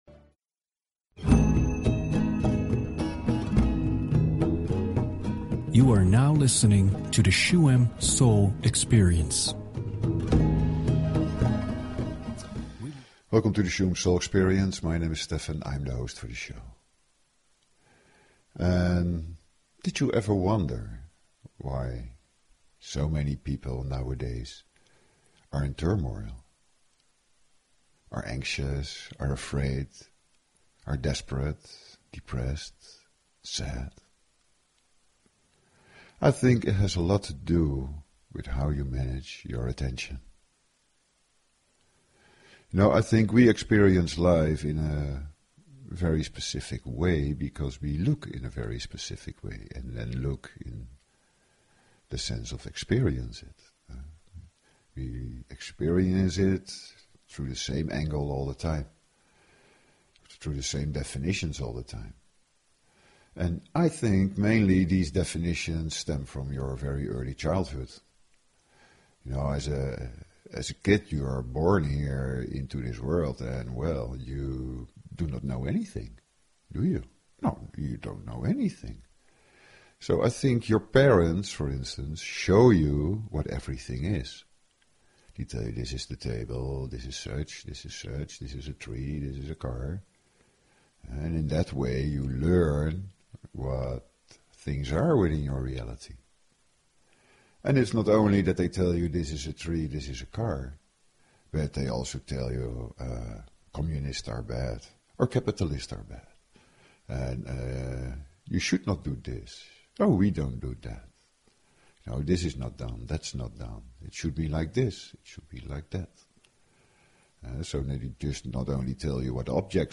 Talk Show
Shuem Soul Experience is a radio show with:
Listening through headphones - especially during the healing - makes it even easier to share in the experience.